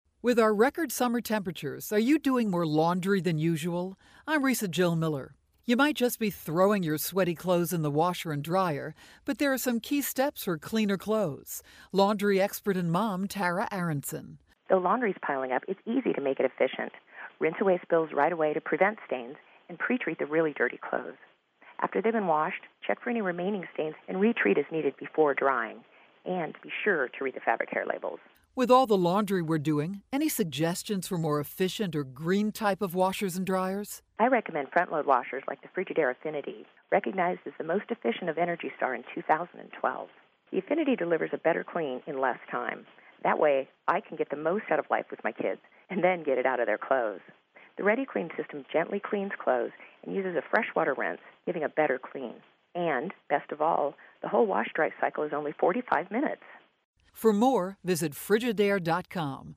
July 31, 2012Posted in: Audio News Release